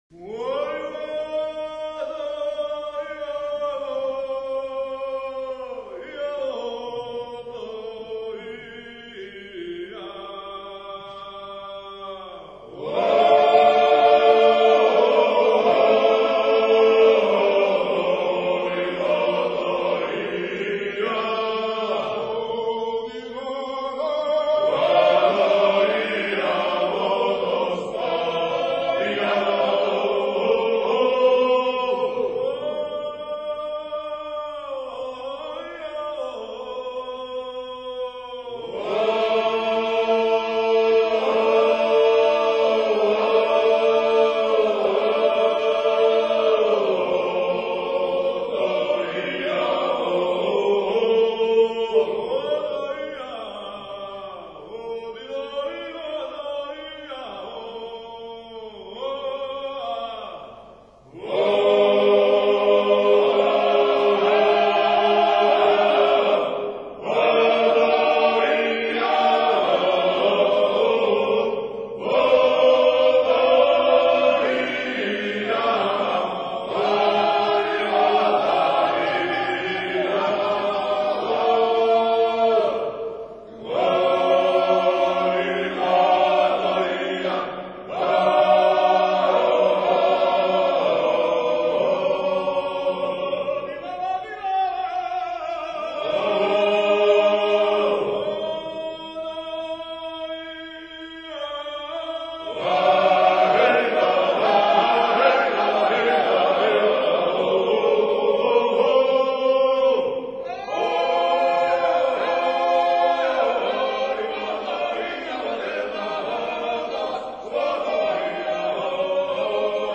A work song typical of Samegrelo region, sung in the field while hoeing.